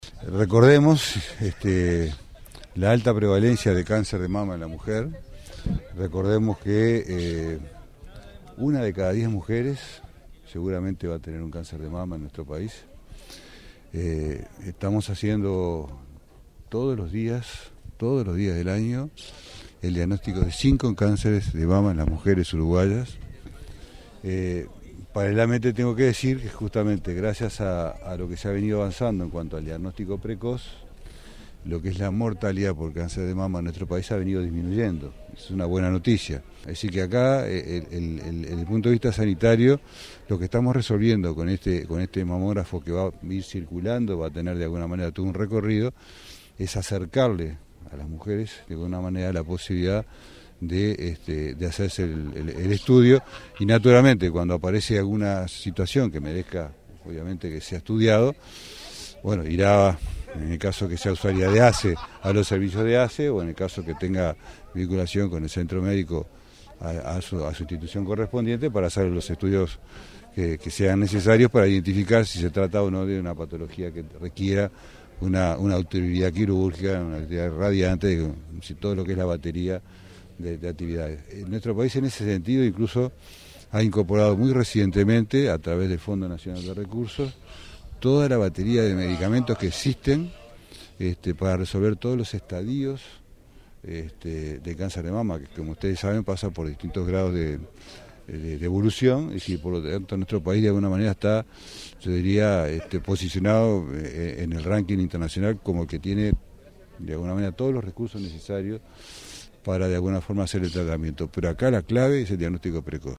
“La clave para tratar el cáncer de mama está en el diagnóstico precoz”, afirmó el ministro de Salud Pública, Jorge Basso, en la entrega de un mamógrafo móvil en Salto para la atención de mujeres mayores de 50 años de prestadores públicos y privados. El vehículo, que recorrerá zonas urbanas y rurales, fue donado por la Comisión Técnico Mixta de Salto Grande.